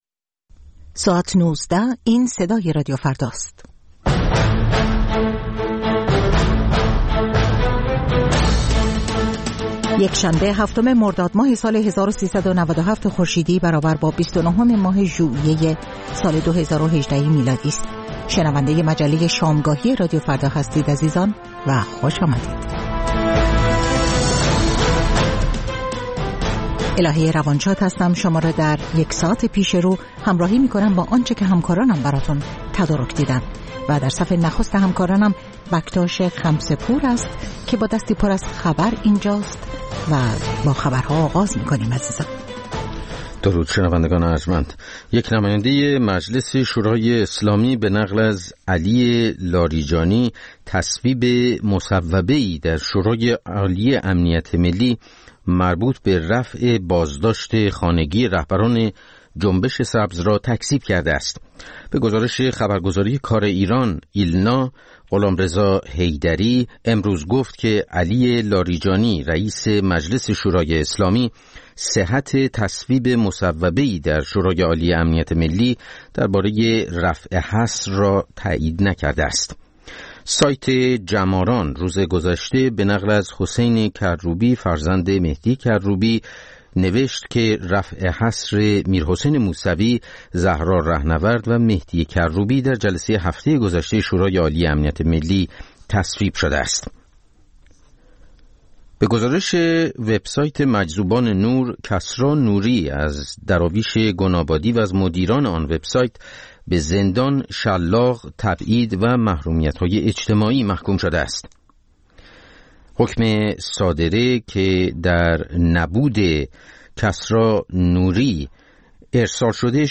مجموعه‌ای متنوع از آنچه در طول روز در سراسر جهان اتفاق افتاده است. در نیم ساعت اول مجله شامگاهی رادیو فردا، آخرین خبرها و تازه‌ترین گزارش‌های تهیه‌کنندگان رادیو فردا پخش خواهد شد.